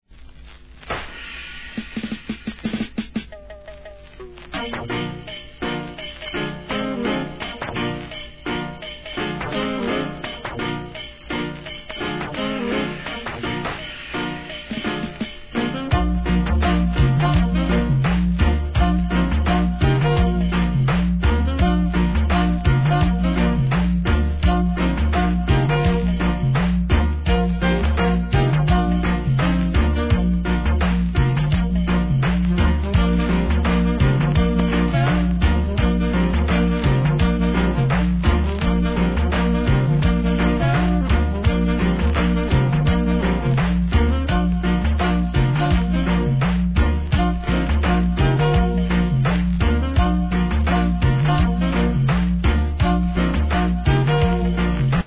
REGGAE
ベースがインパクト大の人気RIDDIM